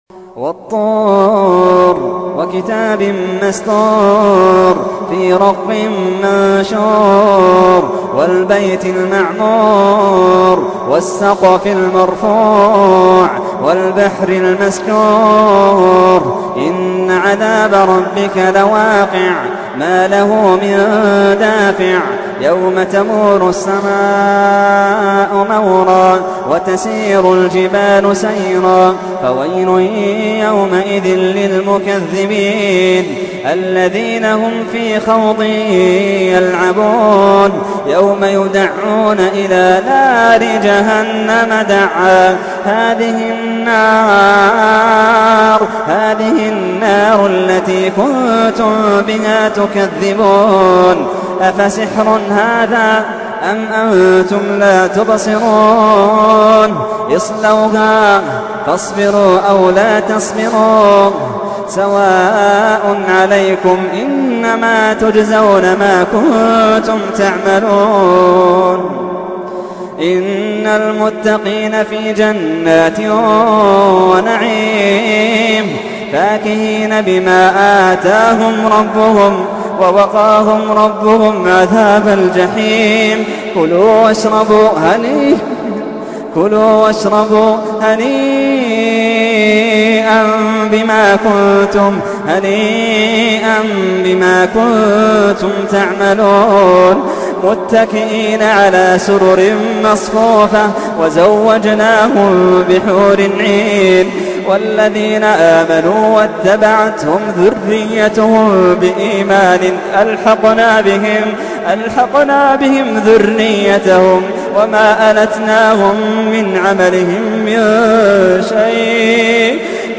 تحميل : 52. سورة الطور / القارئ محمد اللحيدان / القرآن الكريم / موقع يا حسين